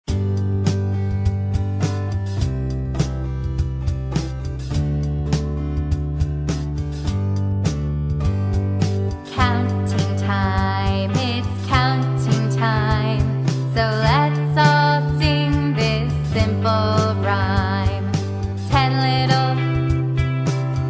Fill-in the Blanks Instrumental